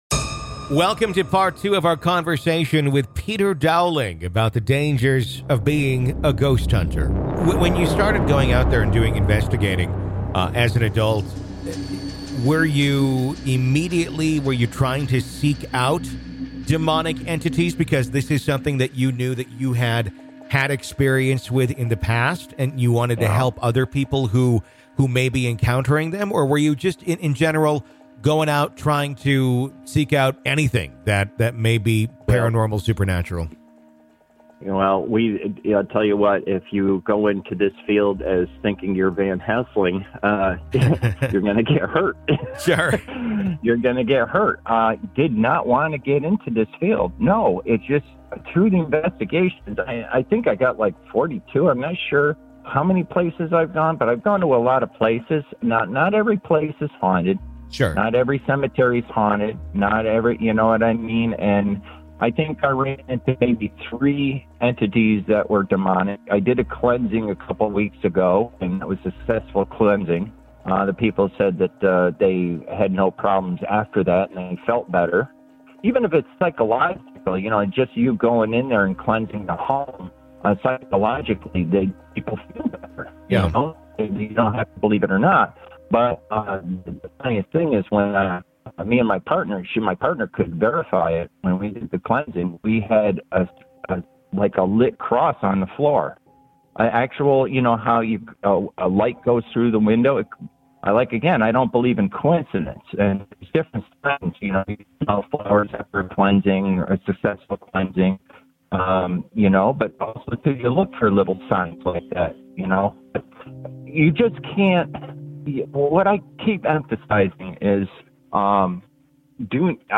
In this chilling interview